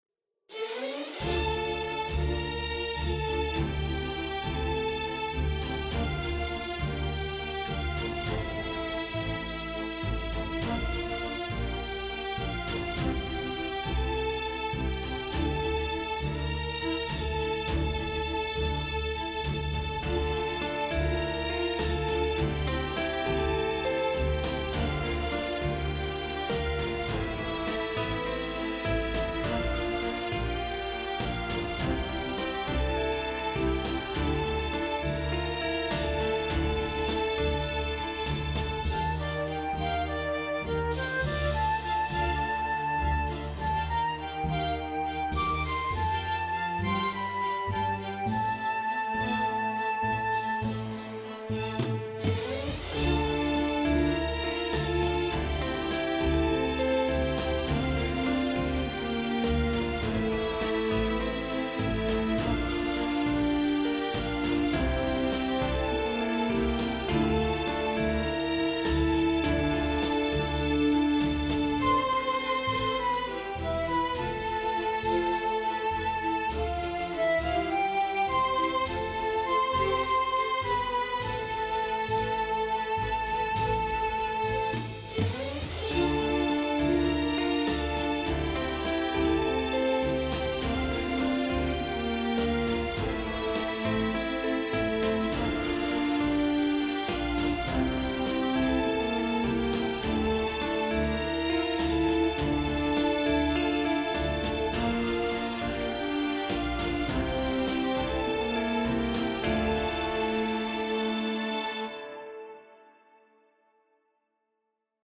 Complete arrangement